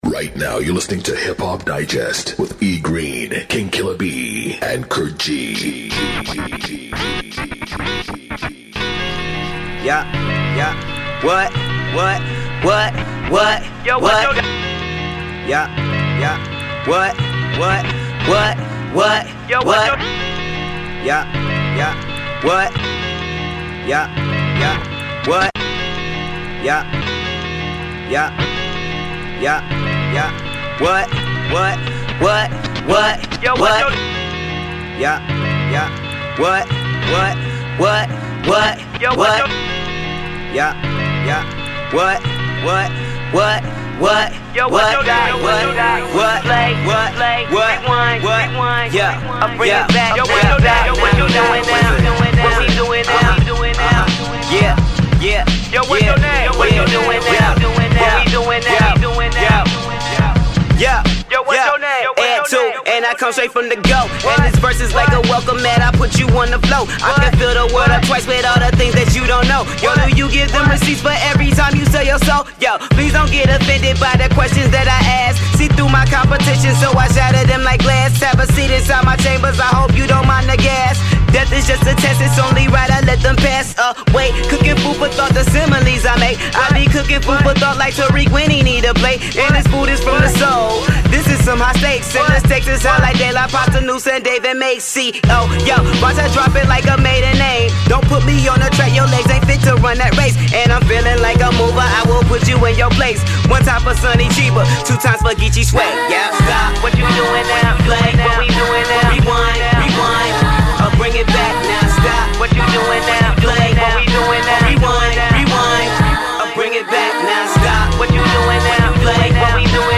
60 minute mix
The Hip-Hop Digest Musik Show Playlist